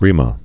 (rēmə)